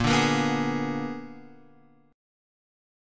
Bb7b9 chord